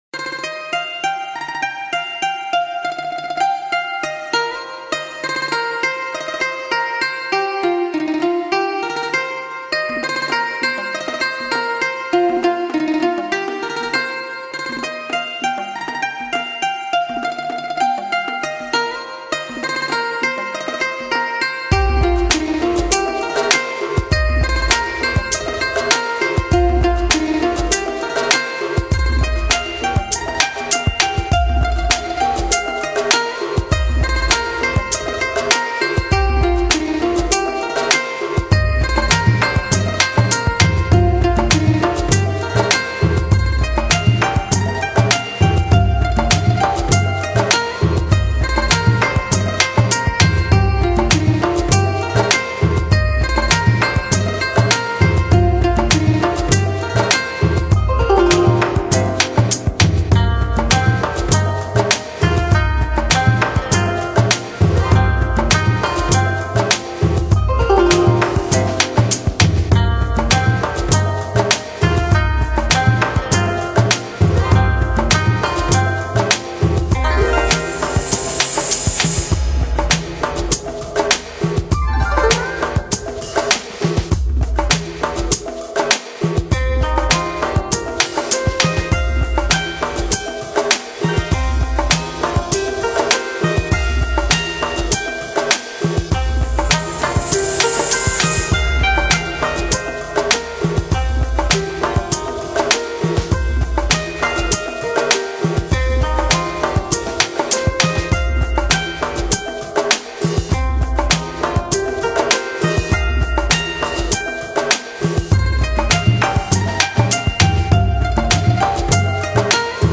Genre: Downtempo